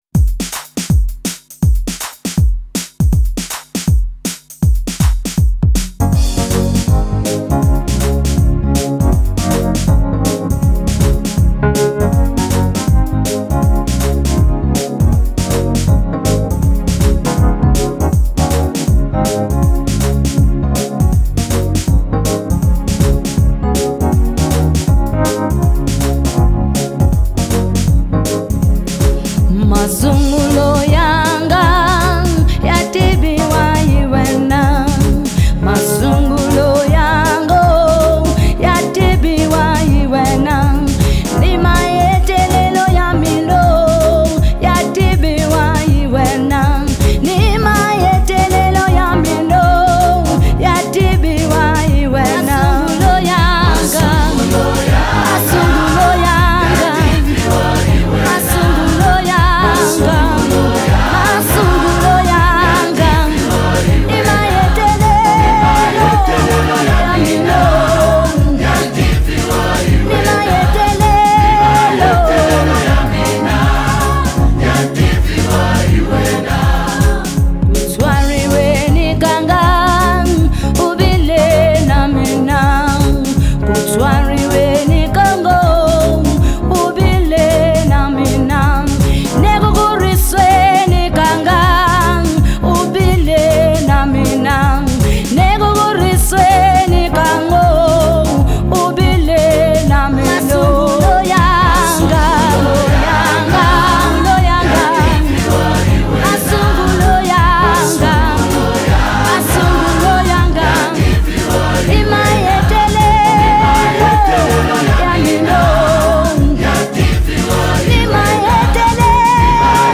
January 30, 2025 Publisher 01 Gospel 0